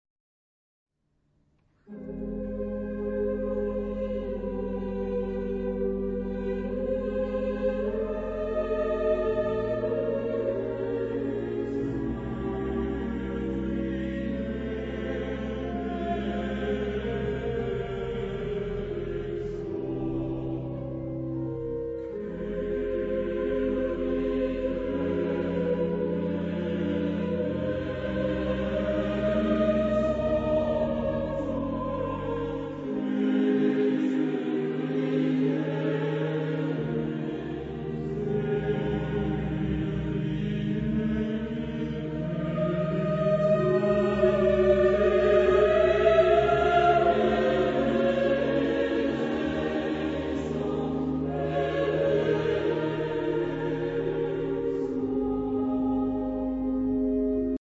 Genre-Style-Forme : Romantique ; Sacré ; Messe
Type de choeur : SATB  (4 voix mixtes )
Instruments : Orgue (1)
Tonalité : fa mineur